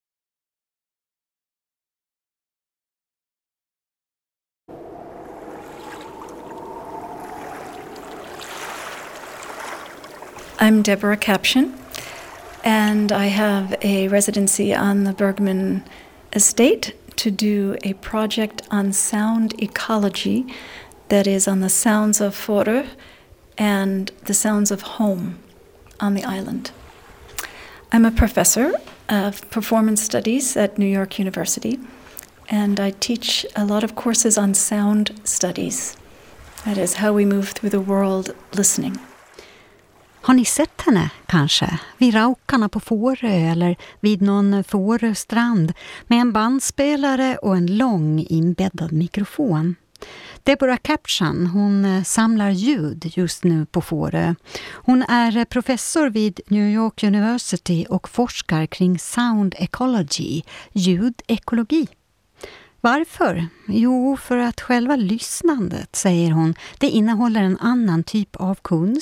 to an excerpt of a sound project in Fårö, Sweden about how sound affects us, how it vibrates the environment and why ways of listening are connected to feelings of home.